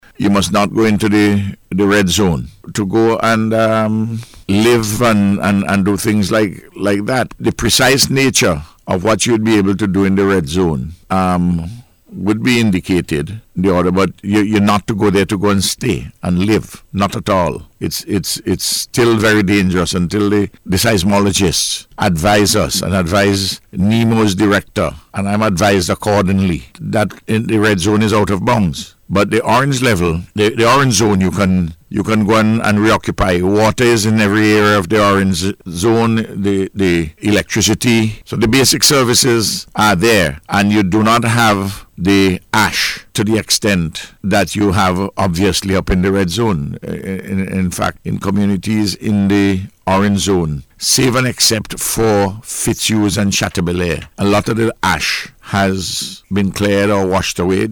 The announcement was made by Prime Minister Dr. Ralph Gonsalves, during the Eyeing La Soufriere Morning Edition today.